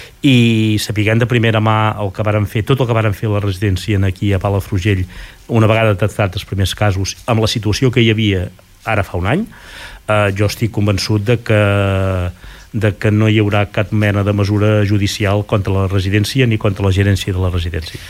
Josep Piferrer, alcalde de Palafrugell, ha explicat en una entrevista al Supermatí de Ràdio Capital que suposen que a partir del proper cap de setmana hi haurà "una allau important de persones" perquè per les noves normatives la gent amb segones residències a Palafrugell poden moure's.